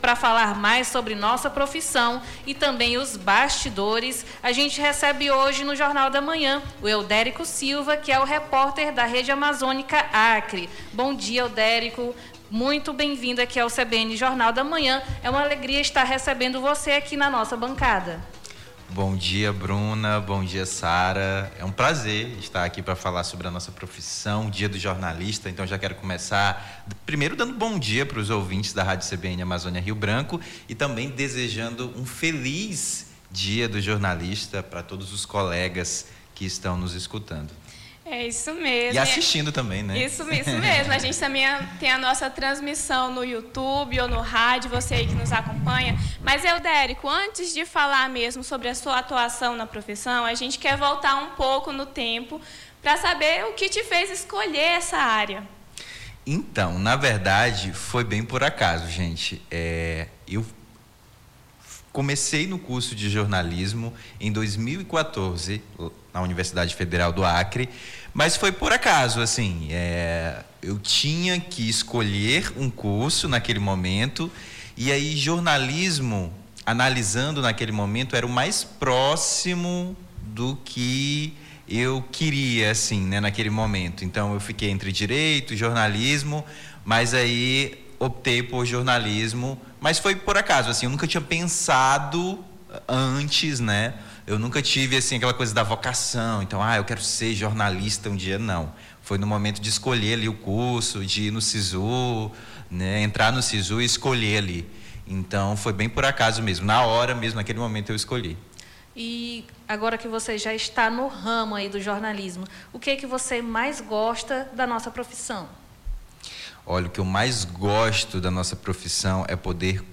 ENTREVISTA DIA DO JORNALISTA